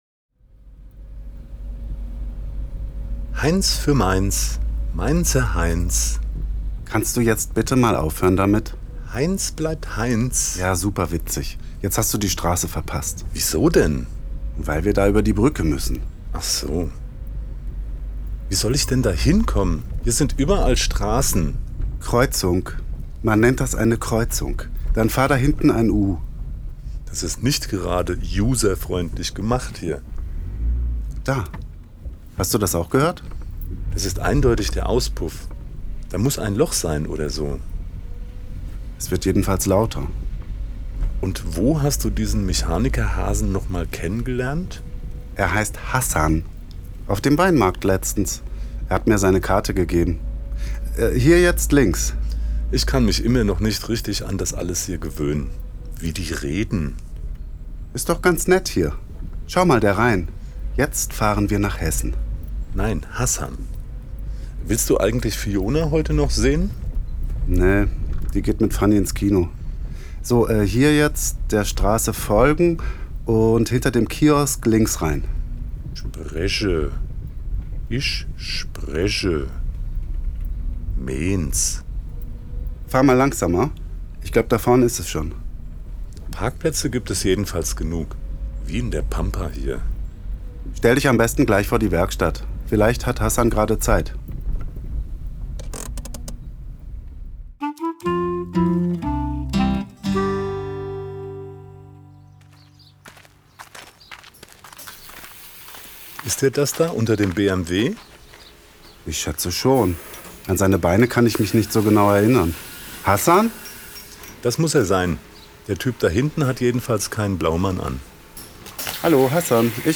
Klarinette
Zentralstudio Mainz